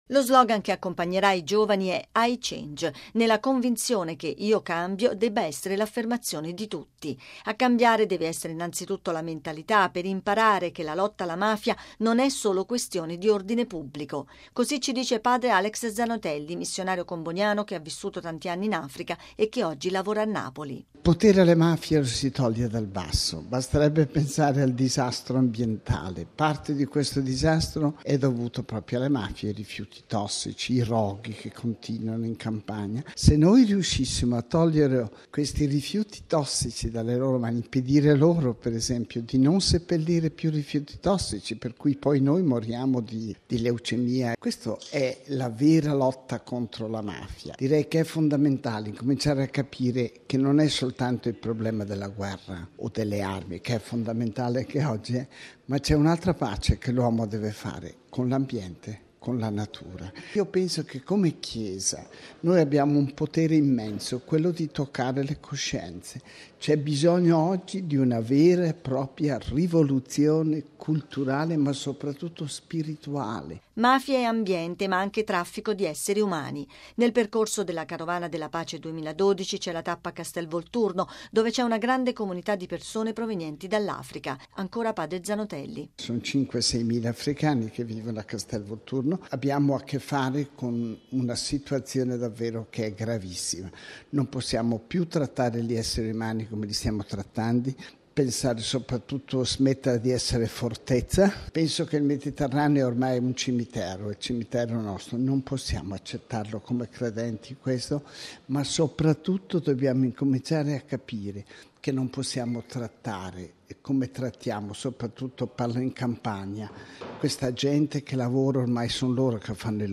Radiogiornale del 19/09/2012 - Radio Vaticana